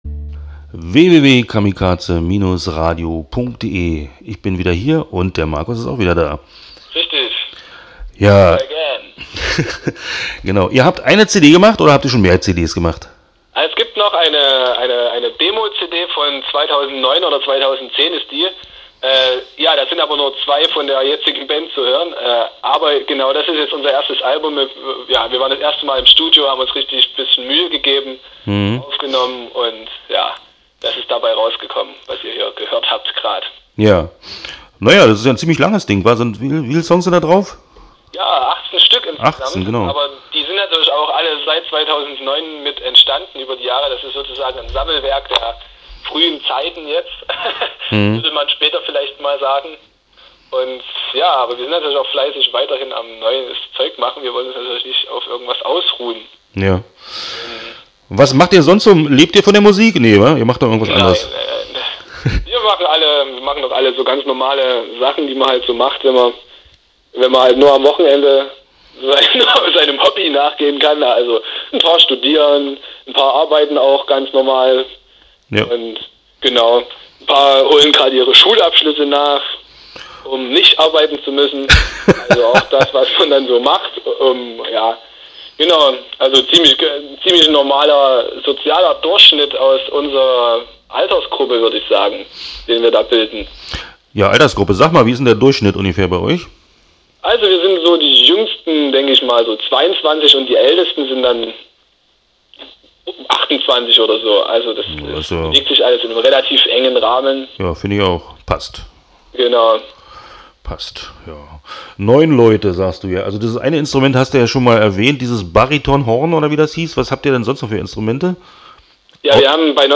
Interview Undenkbar Teil I (7:52)